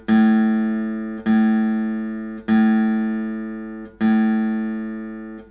The second audio file is the same note, but I have used a filter to remove the first frequency spike at 110Hz, leaving the rest of the note untouched.
Audio file 5: Doctored guitar A note with the fundamental removed
Diagram 4: Spectrum of a guitar A string with the fundamental (110Hz) removed
Here we have something completely unnatural – a harmonic series 220, 330, 440, 550Hz.
guitar-a-110-missing-fundamental-2.mp3